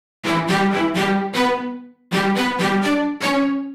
Index of /musicradar/uk-garage-samples/128bpm Lines n Loops/Synths
GA_StaccStr128E-01.wav